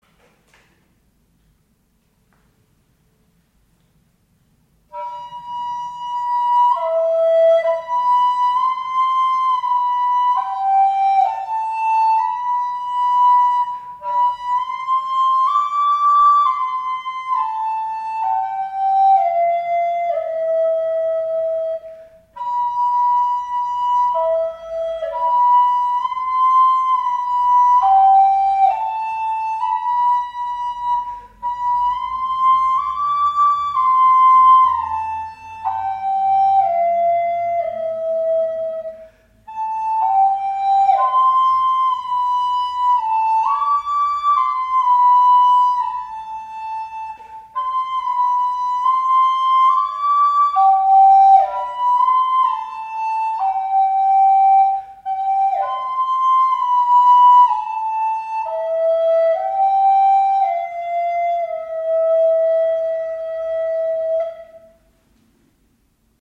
倍音が多く明るい音調であることが判ります。
Luter_Kynseker_Alt.MP3